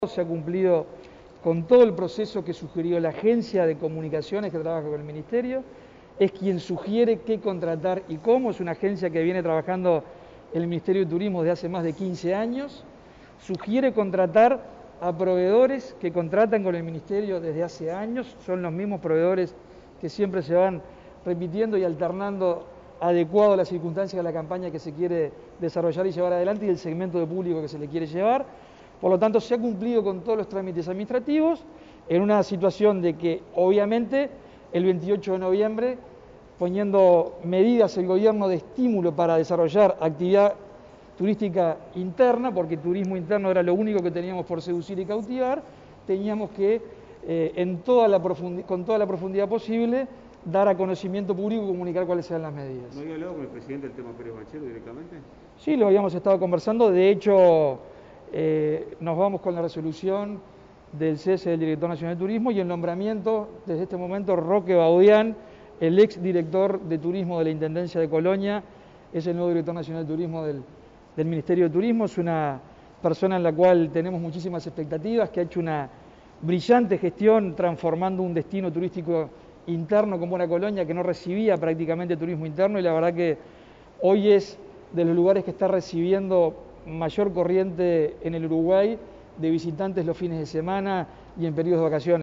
El ministro de Turismo, Germán Cardoso, se refirió este martes en rueda de prensa, tras reunirse con el presidente, Luis Lacalle Pou, a la salida del hoy exdirector nacional de Turismo, Martín Pérez Banchero, por denuncias de este sobre obligaciones de compras directas de la cartera, y expresó que «se ha cumplido con todo el proceso que sugirió la agencia de comunicaciones que trabaja con el ministerio, es quien sugiere qué contratar y cómo».